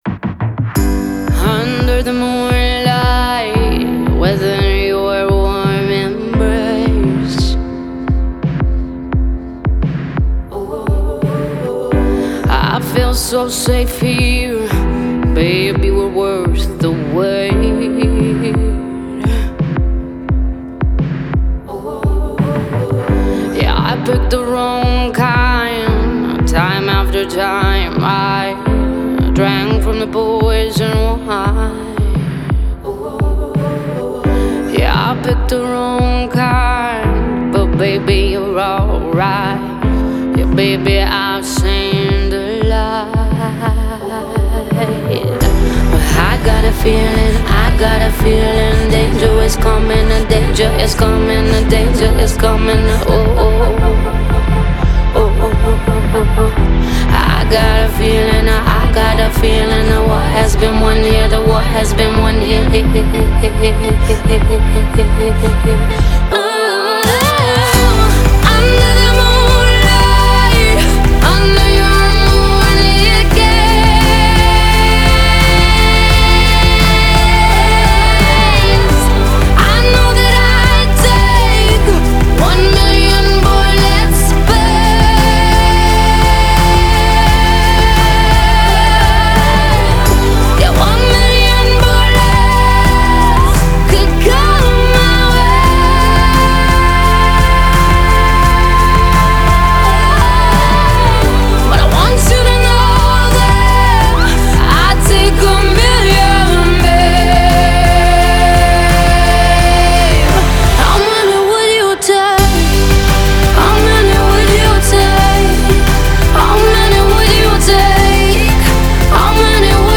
Genre: Alternative, Pop